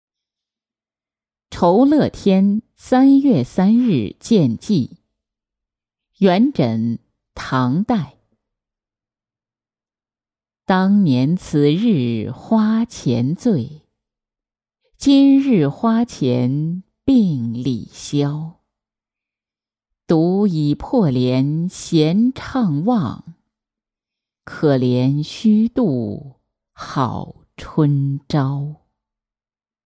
酬乐天三月三日见寄-音频朗读